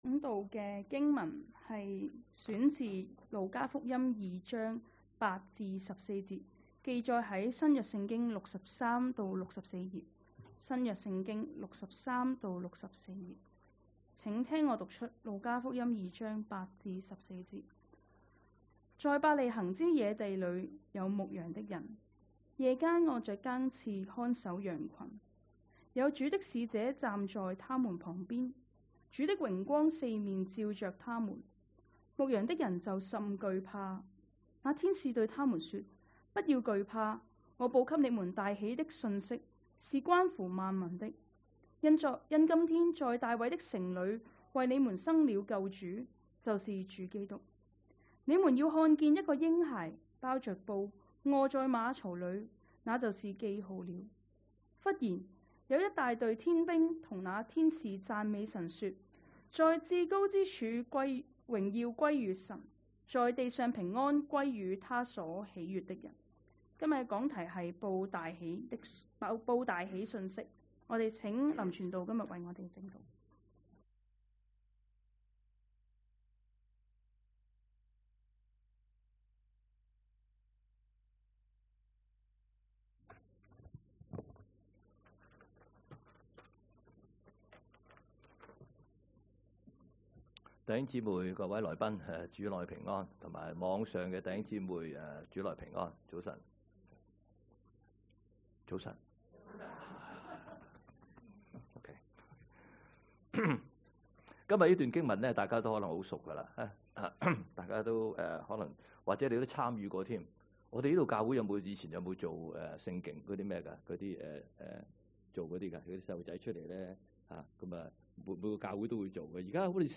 2025年12月21日講道